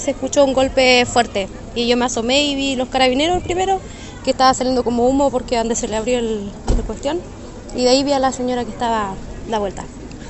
una de las testigos del hecho, relató parte de lo acontecido.